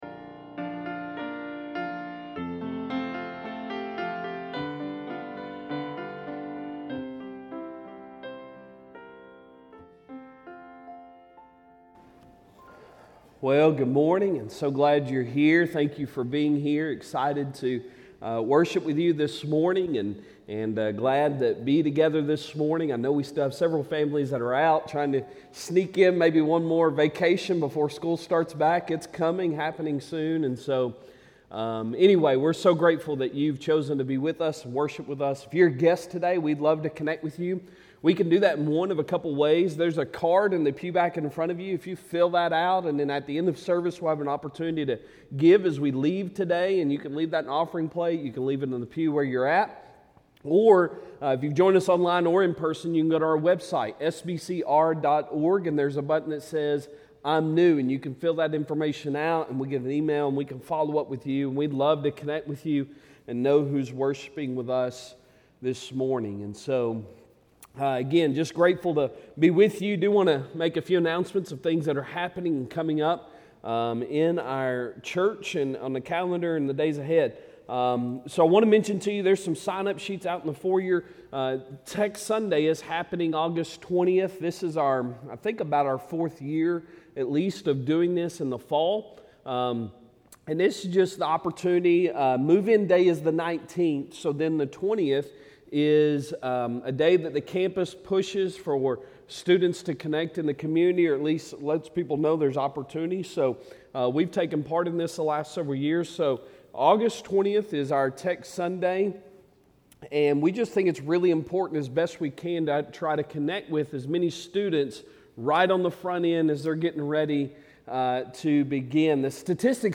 Sunday Sermon August 6, 2023